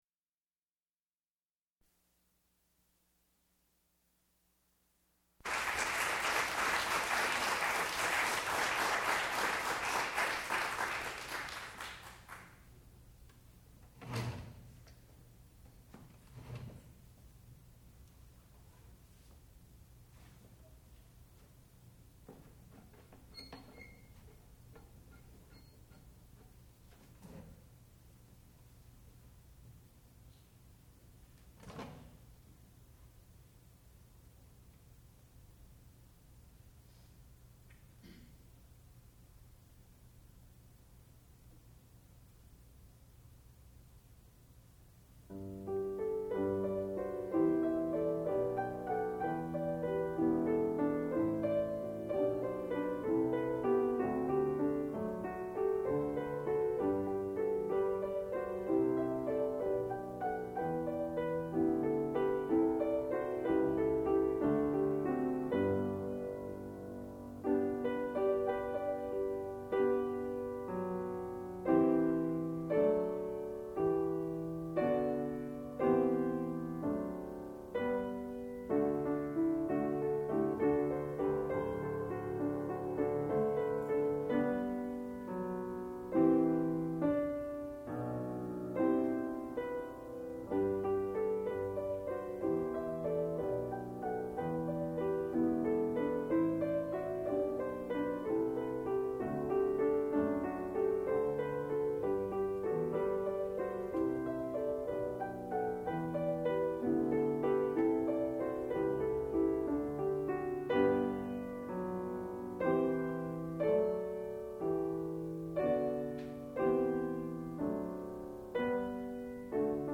sound recording-musical
classical music
piano
Master's Recital